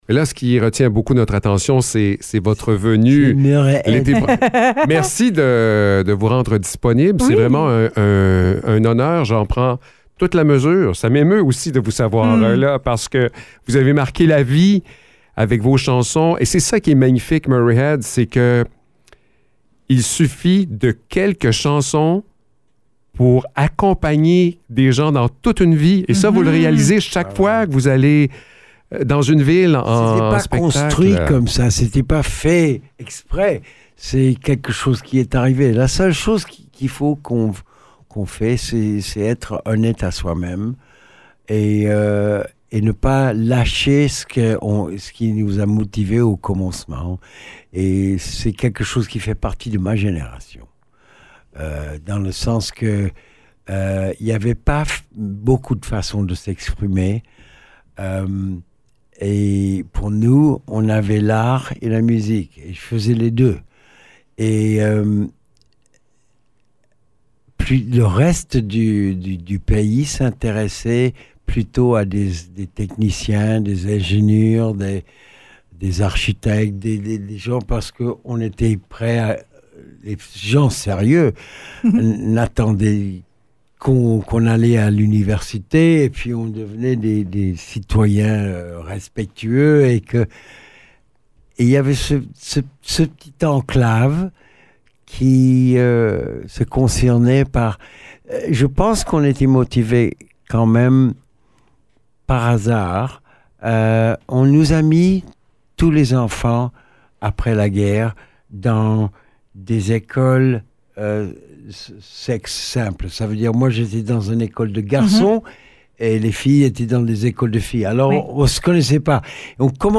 Entrevue avec Murray Head
Le grand Murray Head est venu nous rendre visite en studio pour nous préparer à sa tournée d’adieu.